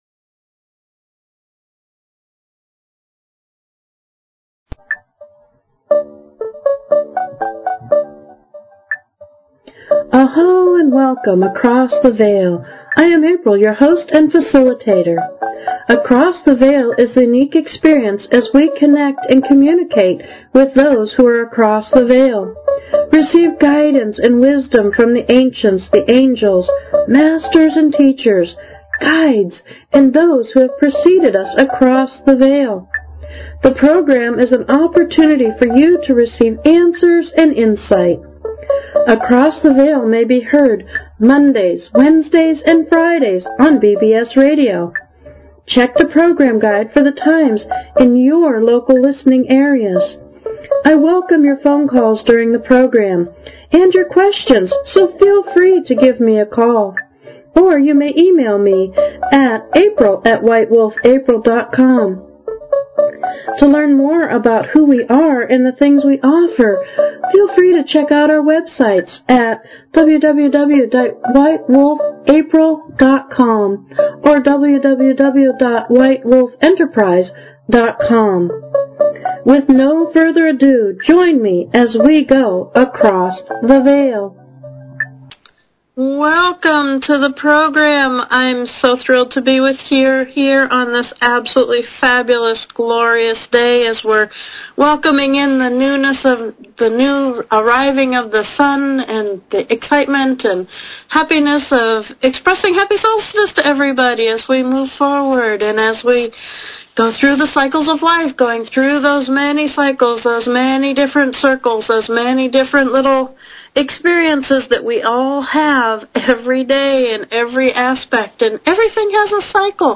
Talk Show Episode
FREE Intuitive Readings Every Week, Every Show, For Every Call-In Across the Veil Please consider subscribing to this talk show.